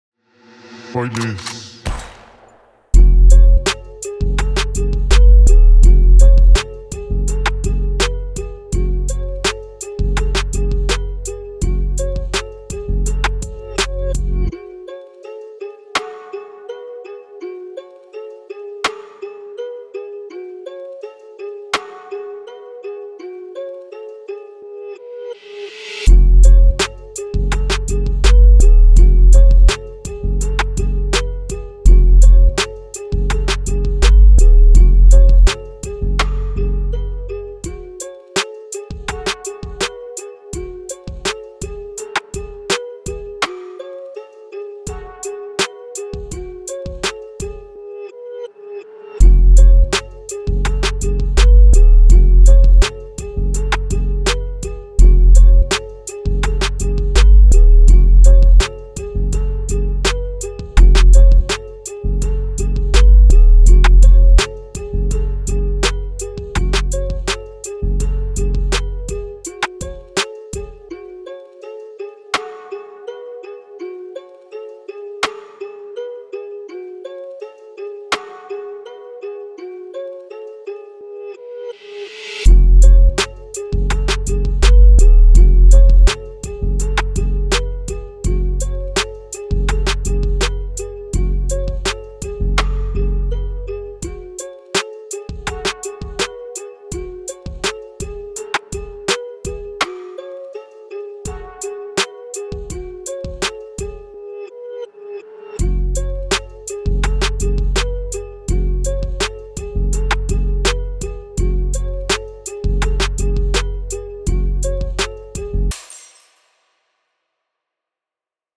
prod-140-bpm.mp3